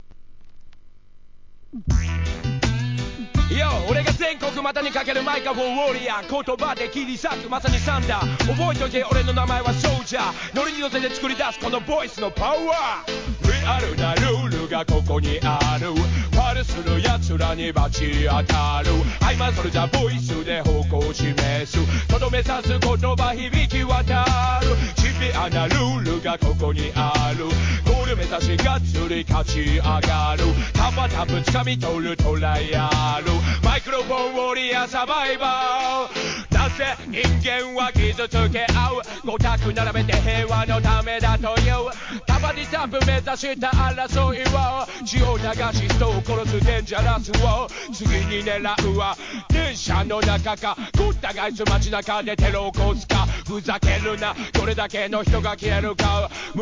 JAPANESE REGGAE